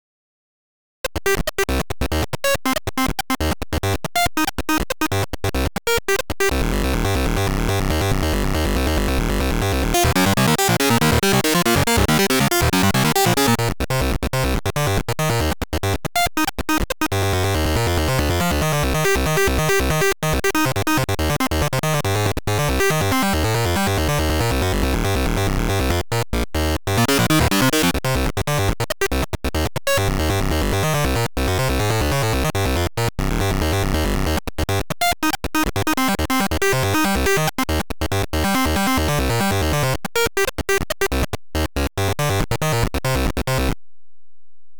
hab grad mit dem kostenlosen xfer 8bit-shaper und nem arpeggiator ein paar sounds generiert, die sich vielleicht so ein bischen wie die alten spielechips anhören...
8bitsound.mp3